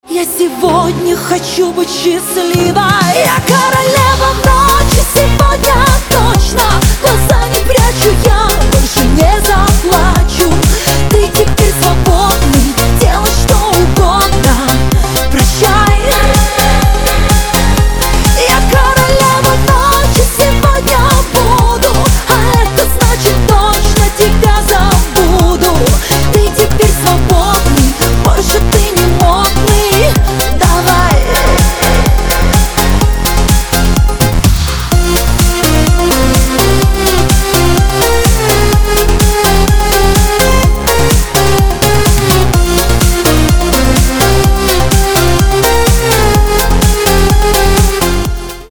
громкие рингтоны , Танцевальные рингтоны
Женские
Поп